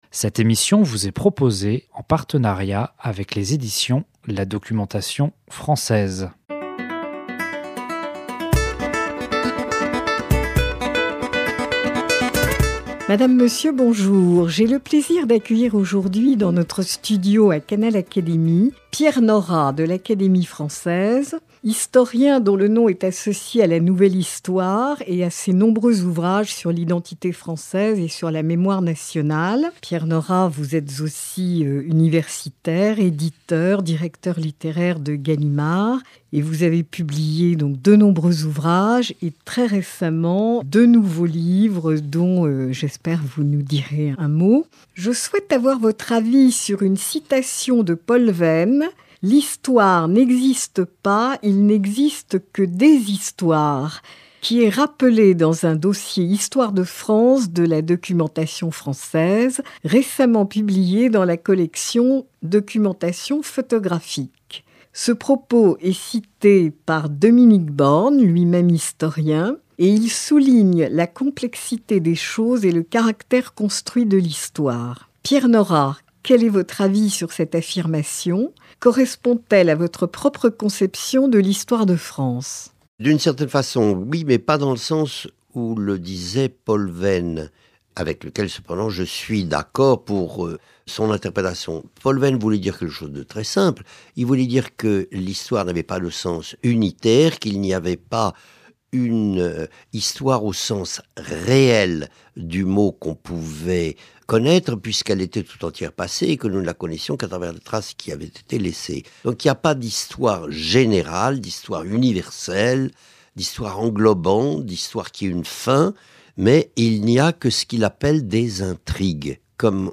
Avec les points de vue d’Emmanuel Le Roy Ladurie et Pierre Nora